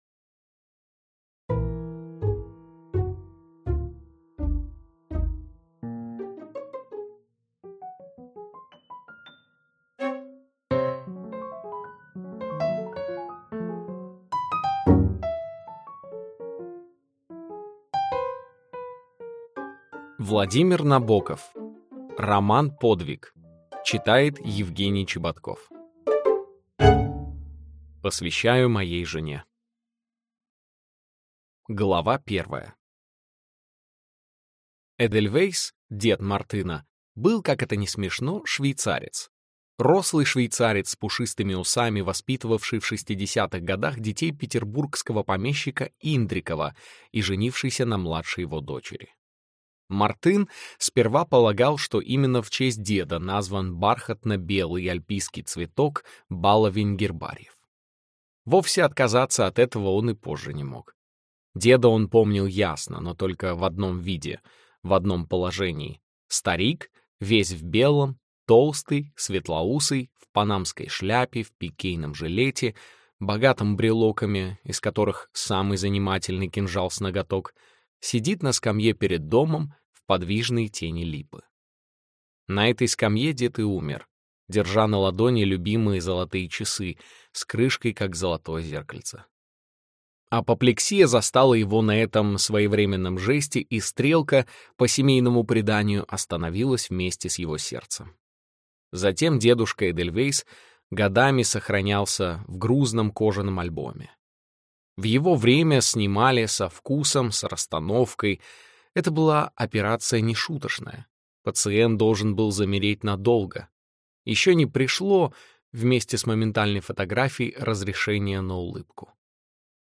Аудиокнига Подвиг | Библиотека аудиокниг